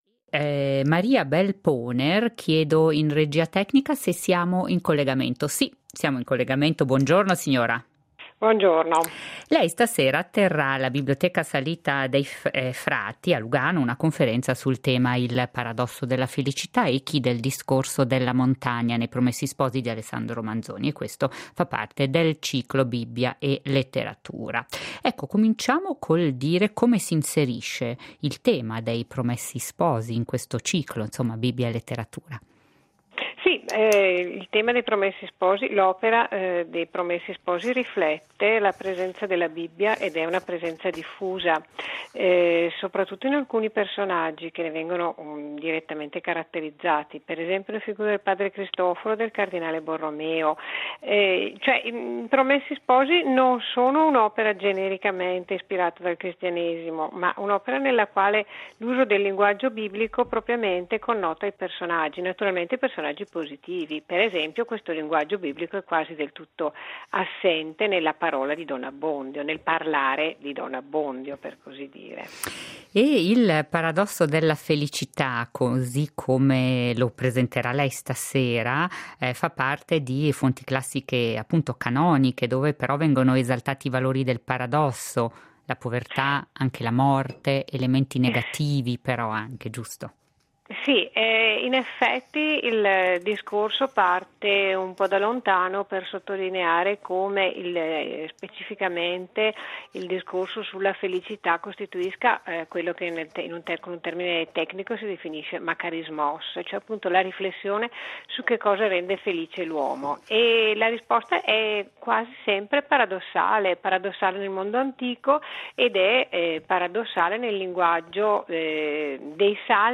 Echi del Discorso della montagna nei "Promessi sposi" di Alesandro Manzoni. Una conferenza